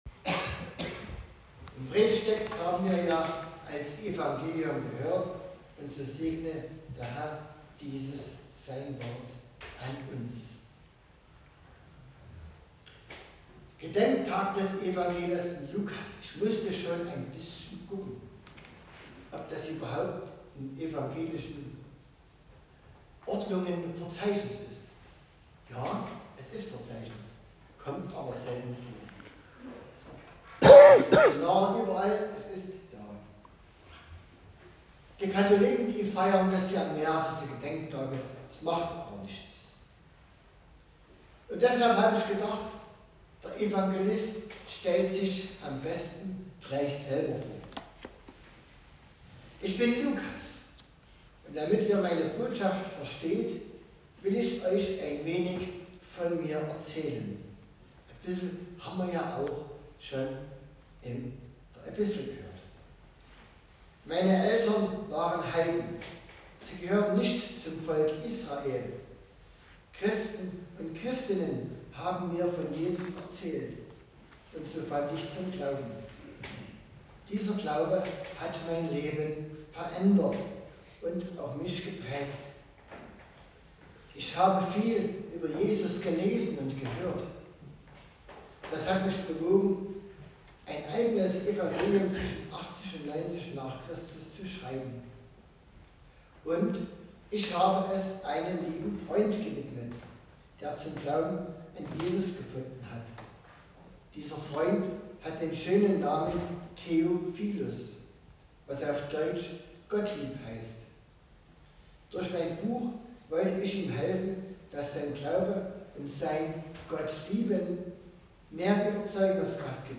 Predigt zum Tag des Apostels Lukas
Gottesdienstart: Predigtgottesdienst Wildenau